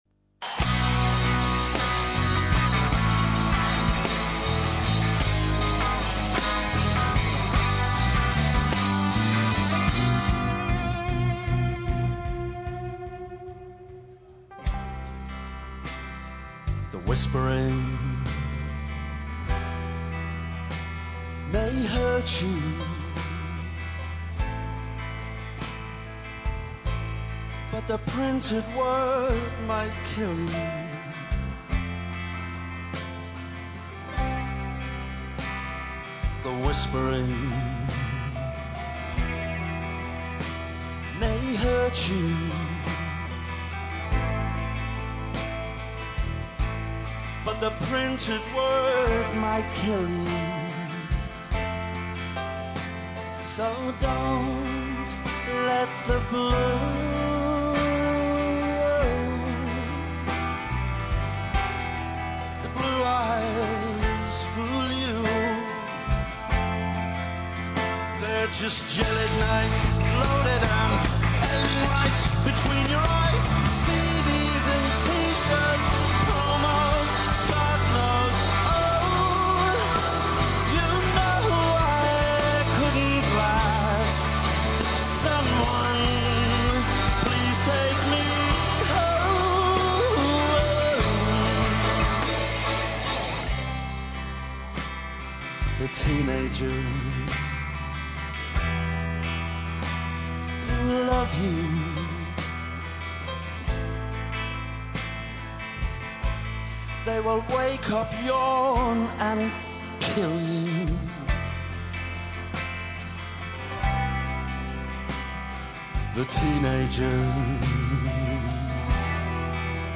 Радио Београд 1, уживо са ЕГЗИТА! На овогодишњем Мејн стејџу Еxит фестивала, очекују вас наступи највећих звезда светске музичке сцене, као и свирке омиљених бендова са ових простора!